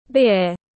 Bia tiếng anh gọi là beer, phiên âm tiếng anh đọc là /bɪər/